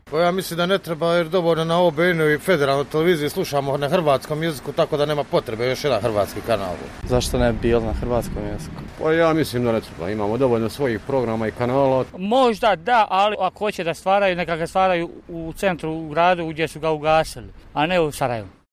Mostarci o kanalu na hrvatskom jeziku